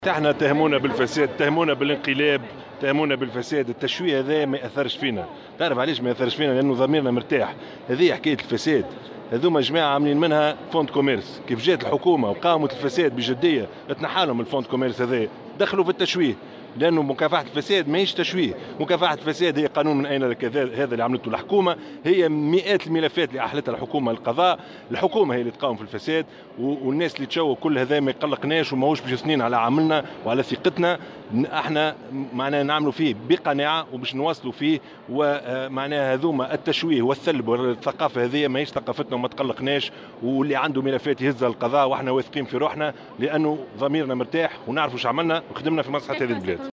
وأضاف في تصريح إعلامي اليوم على هامش افتتاح معرض تونس الدولي للكتاب، أن حملات التشويه والثلب لن تثني حكومته عن مواصلة حربها على الفساد، مؤكدا أنه تمت إحالة مئات الملفات على القضاء.